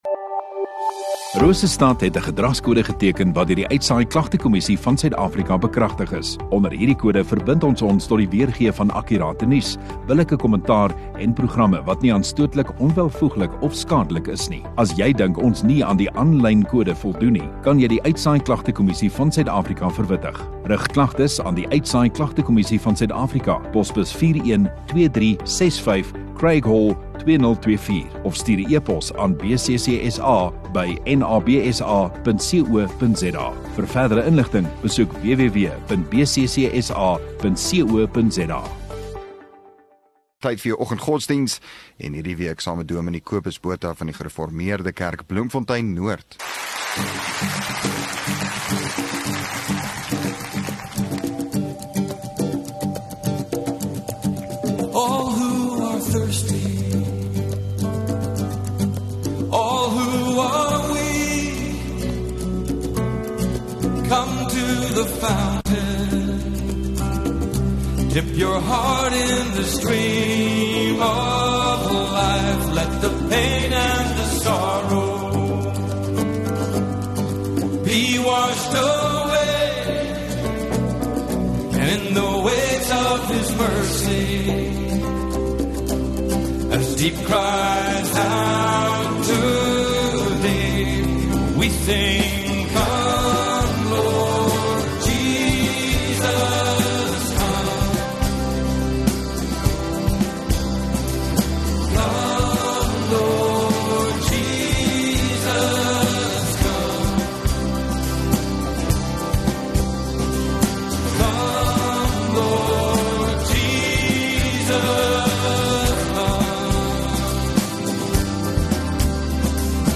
5 Mar Woensdag Oggenddiens